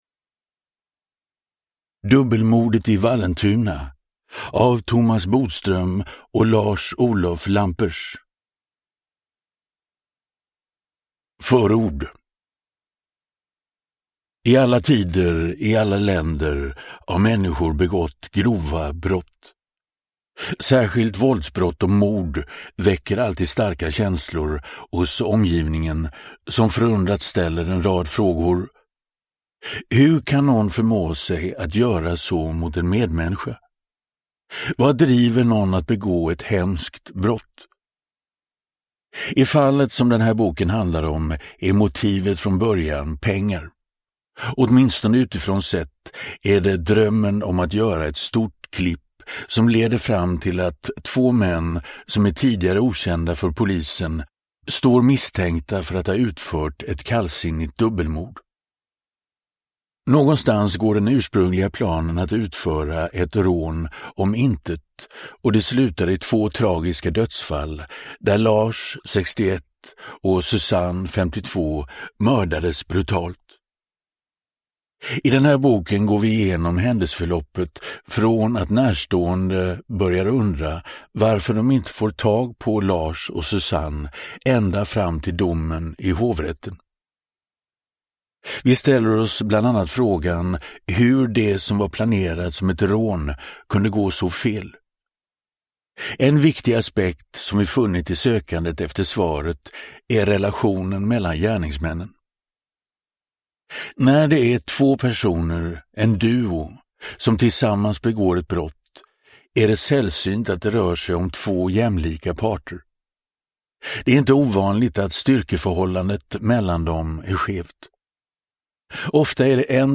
Dubbelmordet i Vallentuna – Ljudbok
Uppläsare: Magnus Roosmann